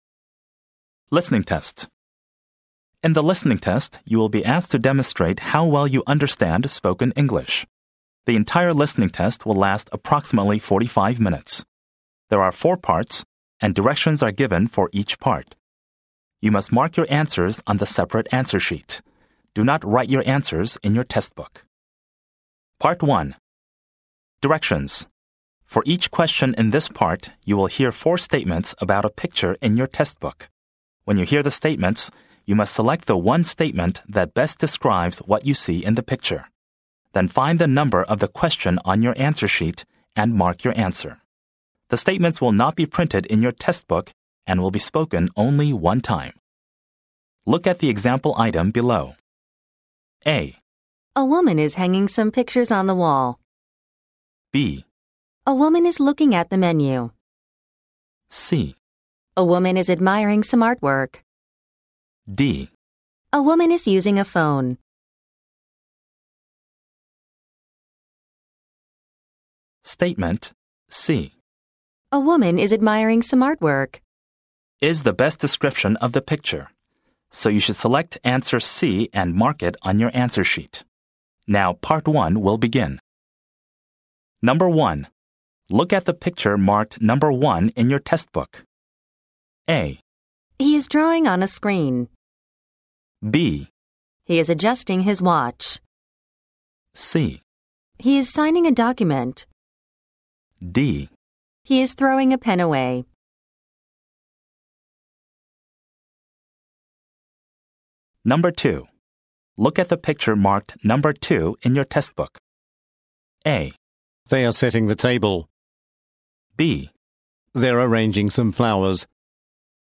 4.MP3多國口音MP3
完全比照新制多益考試規則，精心錄製多國口音，讓你應考更熟悉！